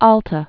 (ältə) also Upper California